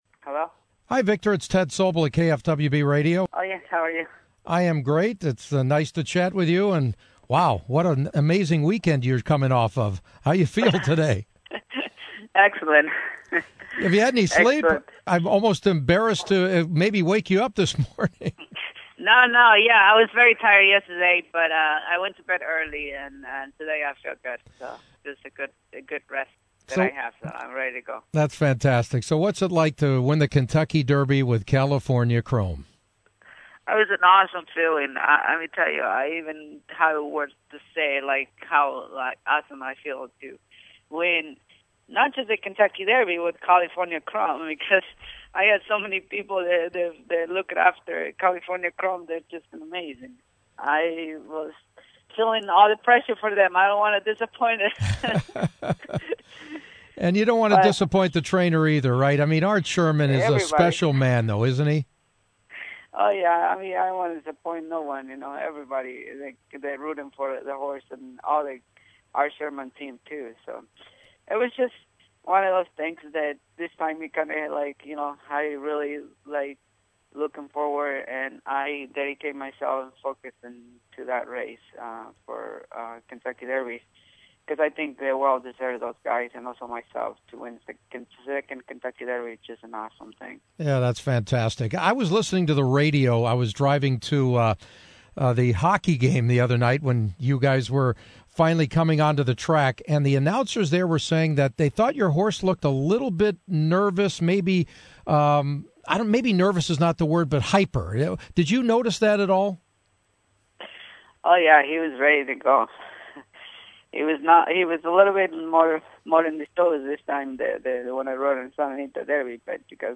He’s not Mr. Ed but California Chrome has a great spokesman in jockey Victor Espinoza and I spoke with him from his Southern California home this morning to get his take on winning Saturday’s Kentucky Derby and his chances to be the first triple crown winner since Affirmed in 1978.